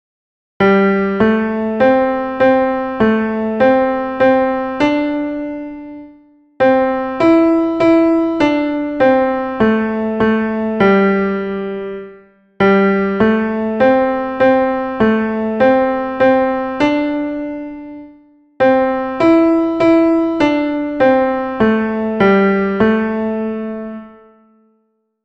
piano demo: St. Flavian